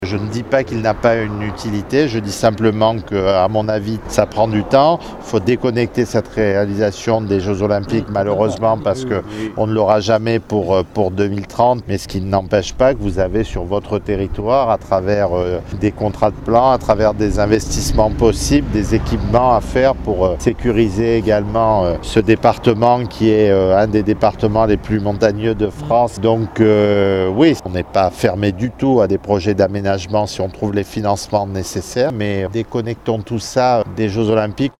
Le ministre en charge des transports Philippe Tabarot.